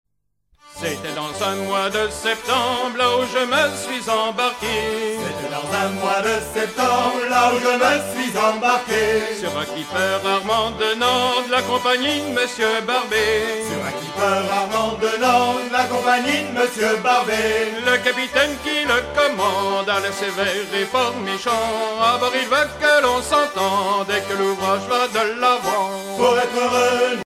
à virer au cabestan
Genre strophique